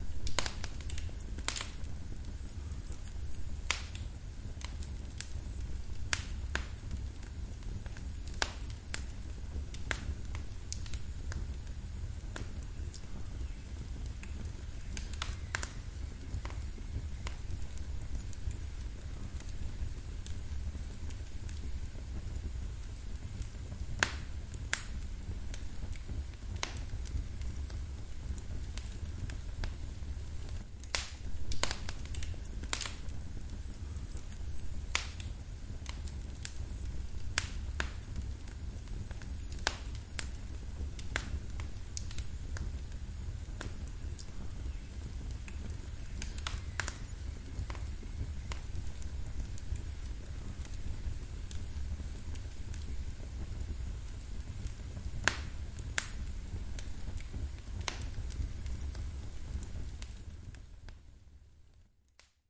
火(木头)
描述：烧木记录。 1分钟
标签： 火焰 现场记录 消防
声道立体声